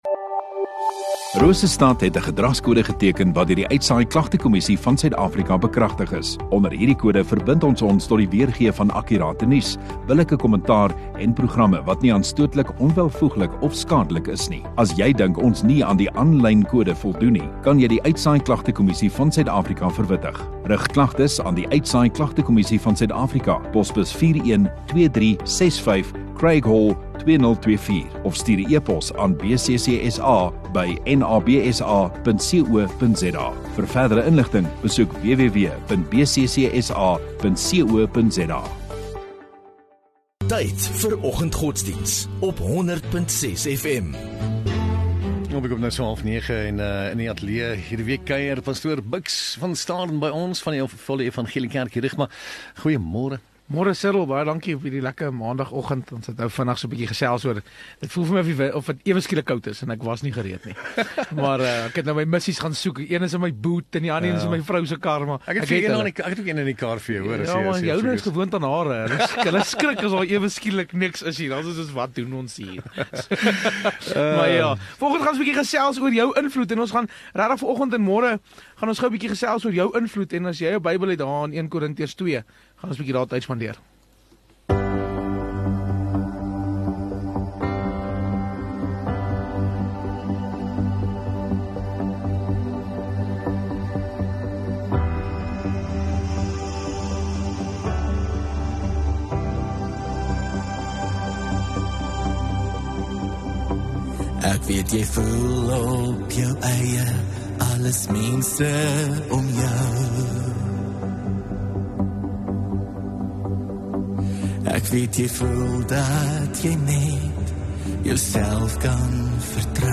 27 May Maandag Oggenddiens